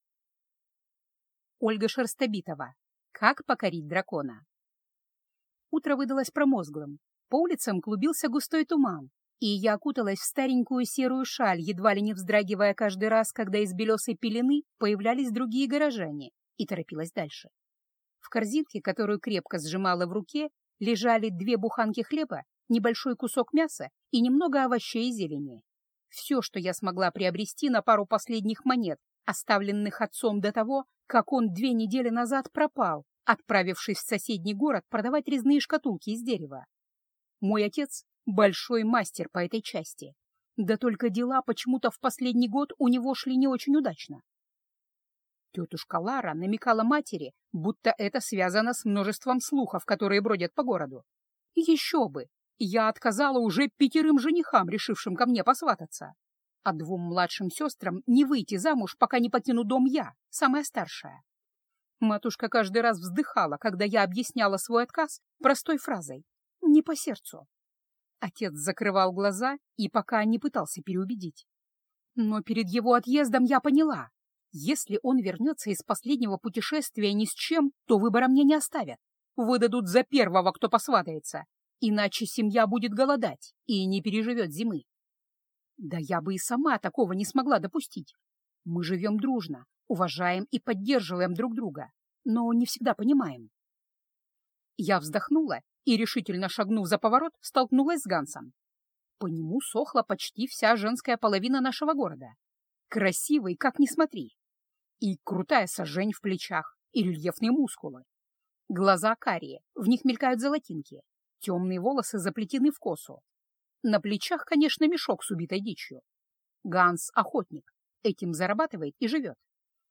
Аудиокнига Как покорить дракона | Библиотека аудиокниг
Прослушать и бесплатно скачать фрагмент аудиокниги